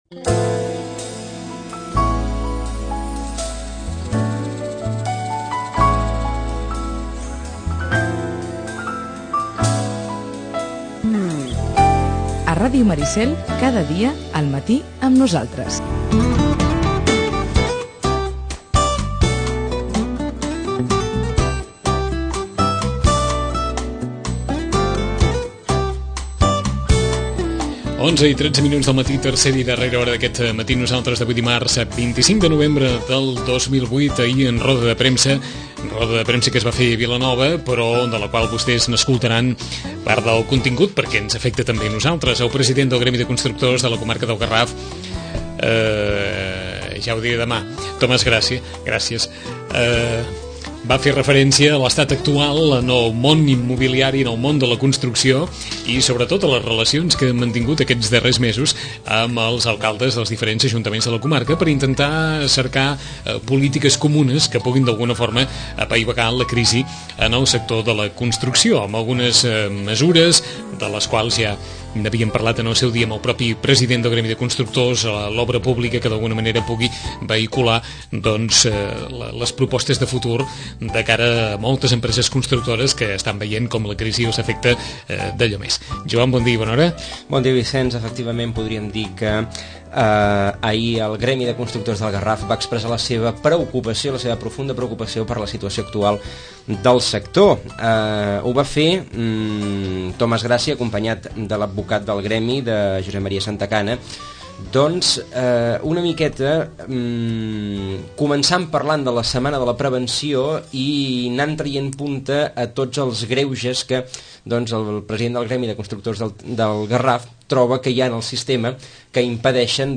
Roda de premsa